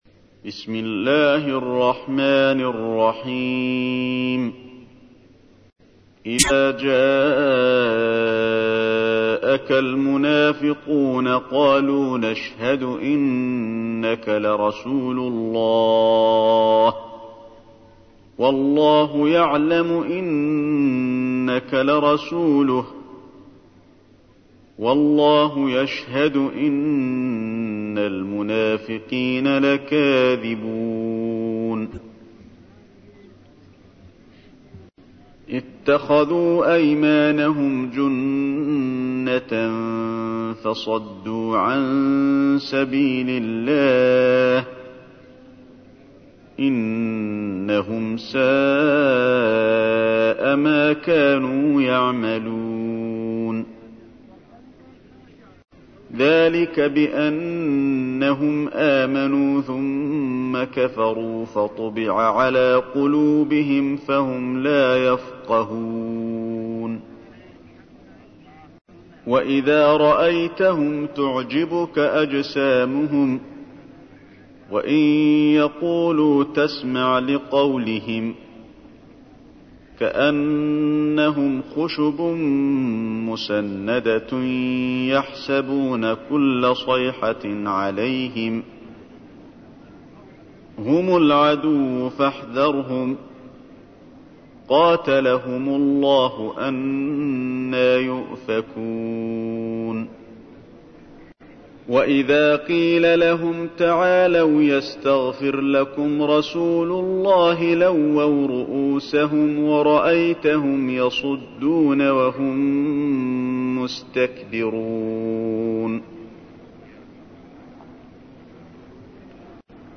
تحميل : 63. سورة المنافقون / القارئ علي الحذيفي / القرآن الكريم / موقع يا حسين